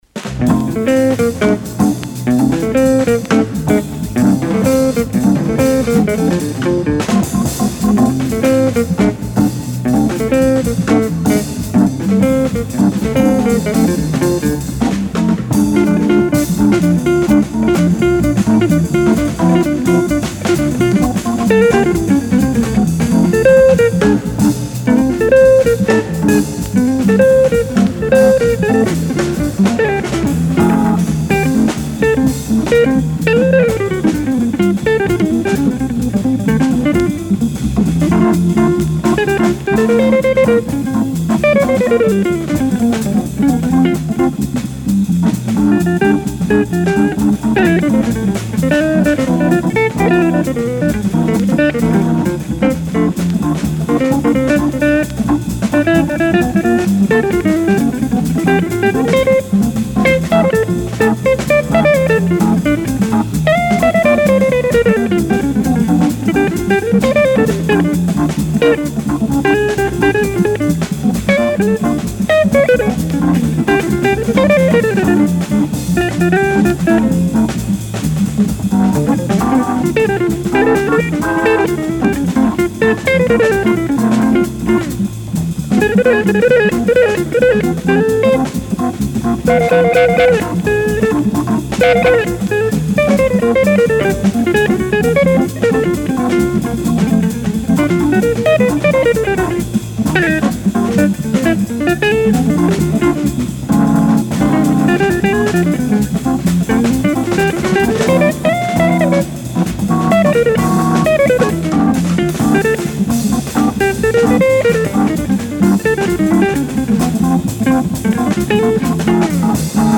• Recorded February 26, 1958 at Manhattan Towers NYC